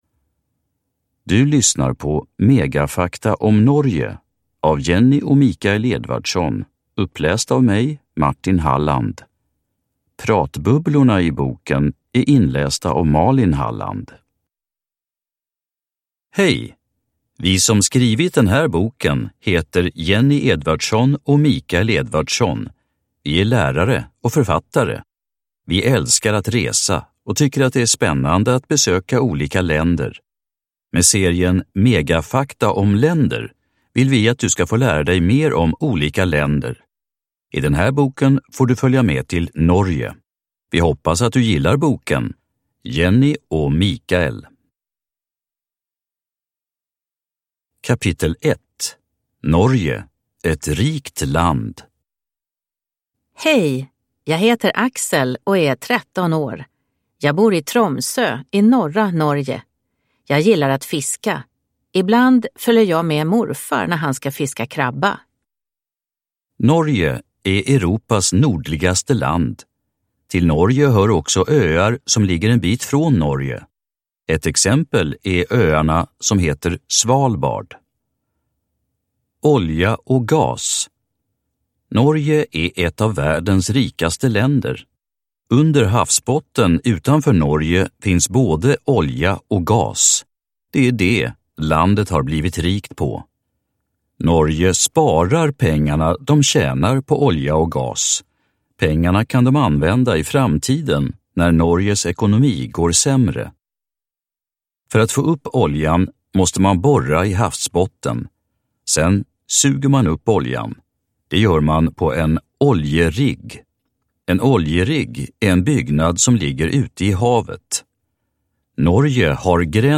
Megafakta om länder. Norge – Ljudbok